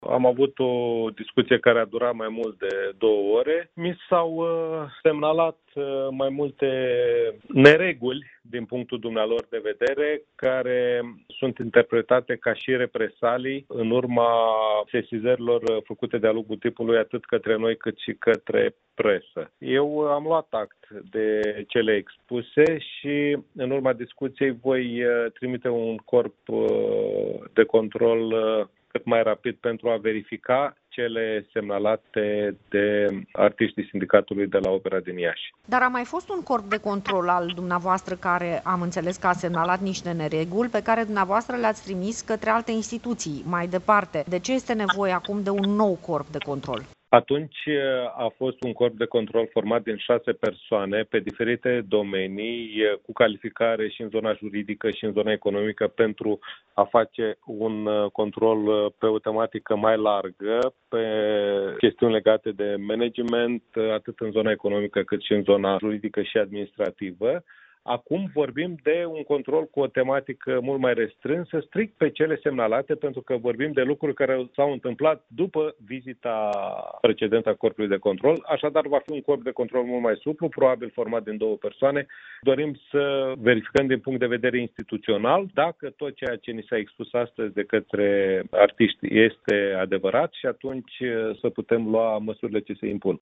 Ministrul Bogdan Gheorghiu a declarat, pentru Radio Iaşi, că a discutat timp de două ore cu reprezentanţii sindicatelor şi că a decis trimiterea unui nou Corp de Control la instituţia ieşeană: